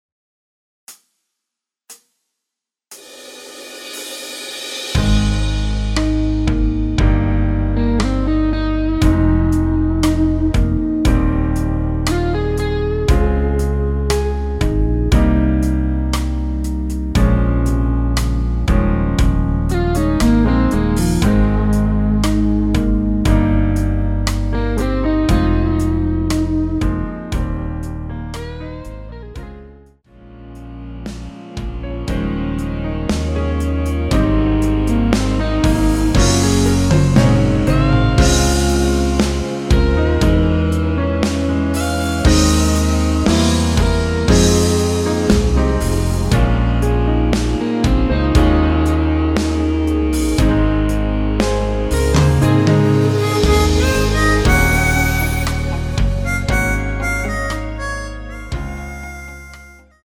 전주없이 시작하는 곡이라 카운트 4박 넣어 놓았습니다.(미리듣기 참조)
원키에서(-1)내린 MR입니다.
Ab
앞부분30초, 뒷부분30초씩 편집해서 올려 드리고 있습니다.
중간에 음이 끈어지고 다시 나오는 이유는